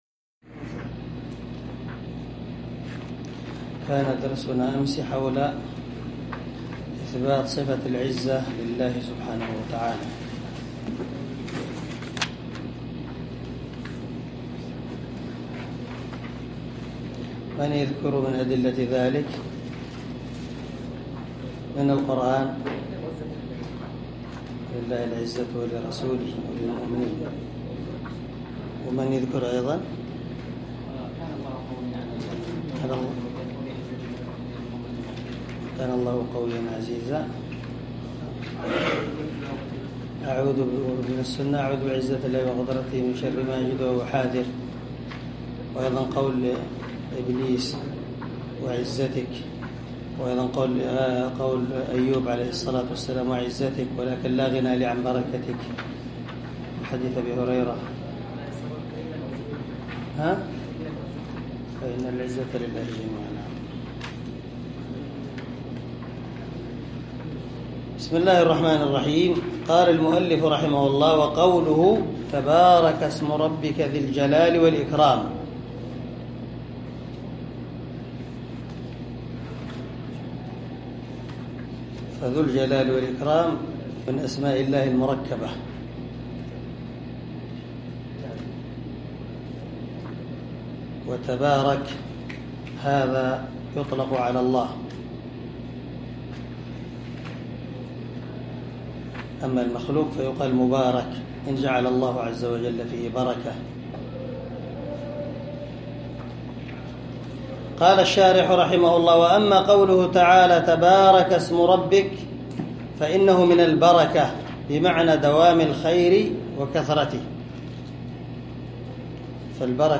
عنوان الدرس: الدرس الثالث الثلاثون
دار الحديث- المَحاوِلة- الصبيحة.